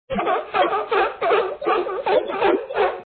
seals2.mp3